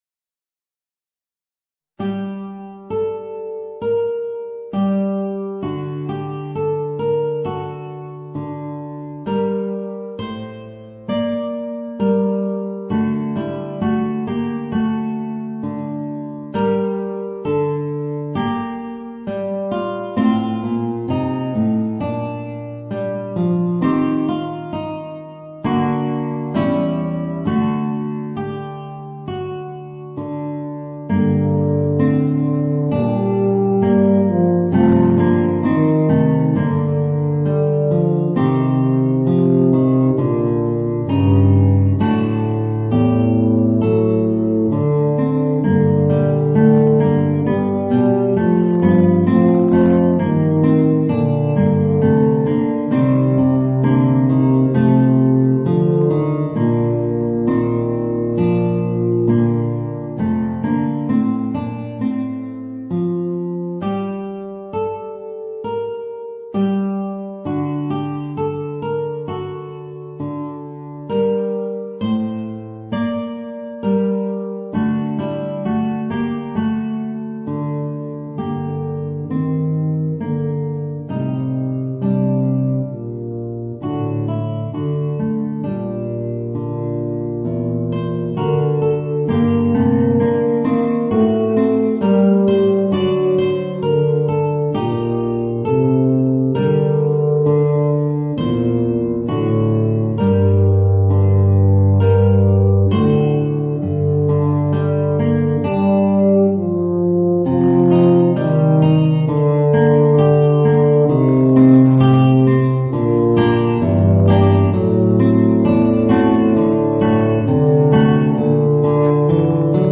Voicing: Eb Bass and Guitar